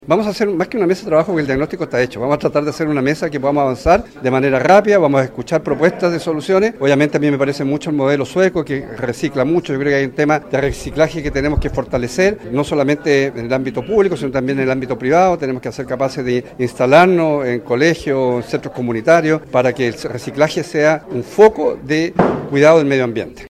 En conversación con Radio Bío Bío, Santana apuntó que la idea de contar con un gran centro de tratamiento integral entre Castro y Dalcahue debe ser consensuada con todas las comunas, por eso dijo que se reunirá con los alcaldes del archipiélago para abordar de manera urgente esta problemática.